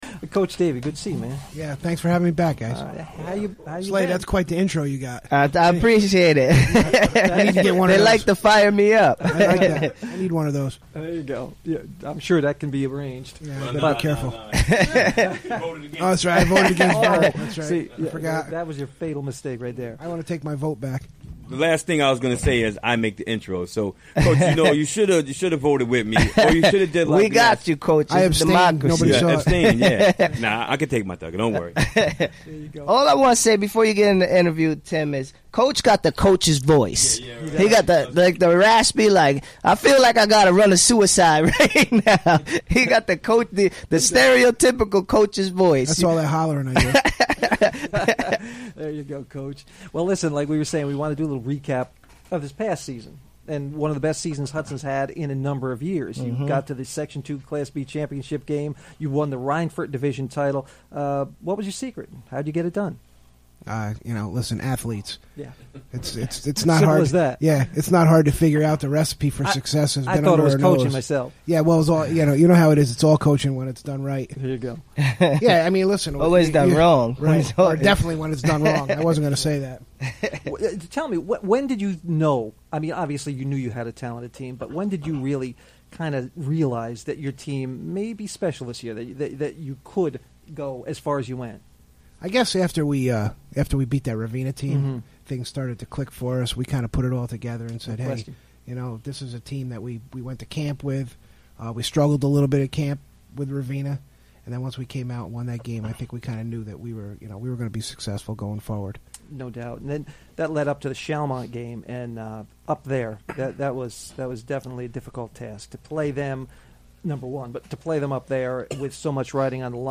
Recorded during the WGXC Afternoon Show Wednesday, April 19, 2017.